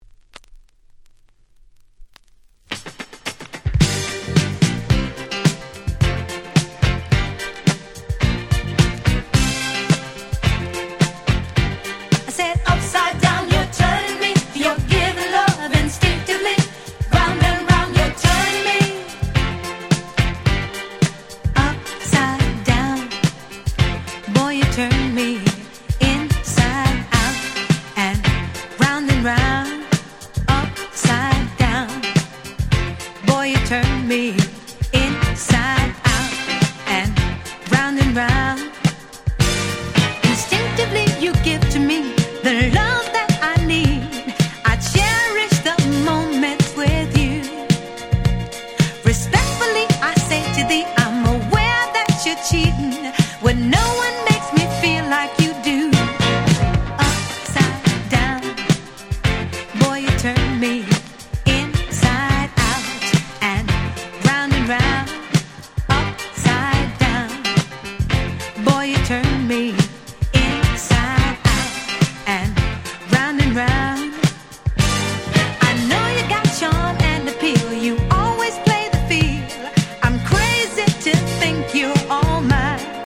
Disco Boogieド定番！！